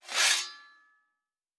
Fantasy Interface Sounds
Blacksmith 09.wav